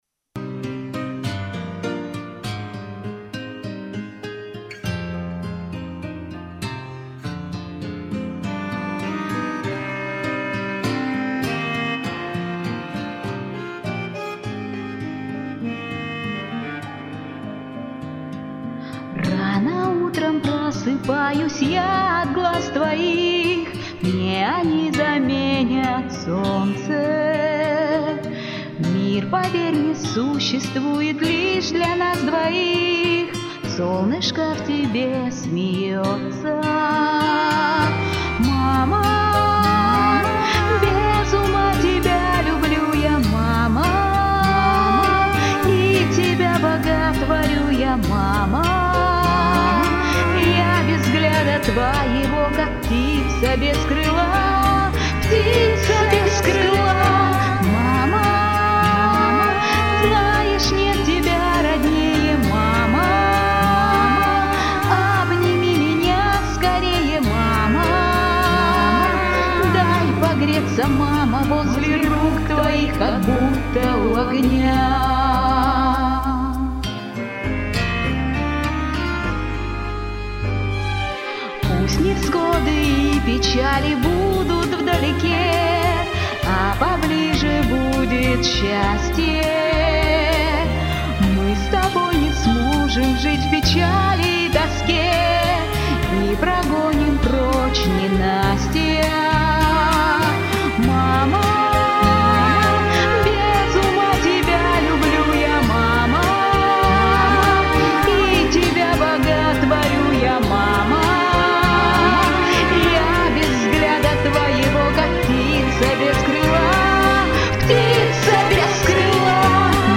Детские